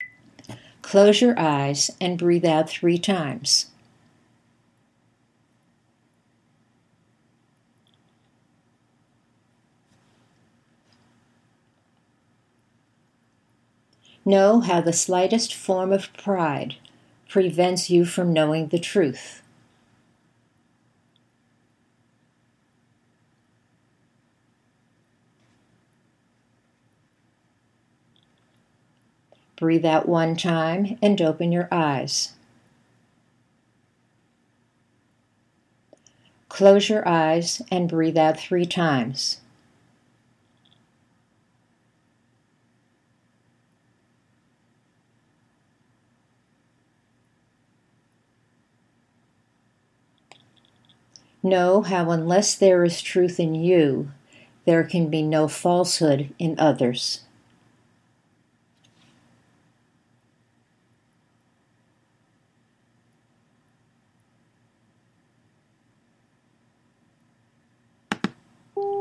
At the end of each exercise you’ll find a quiet space of 8 seconds to focus on your images.
The simple version: until you hear the beep, there may be another instruction.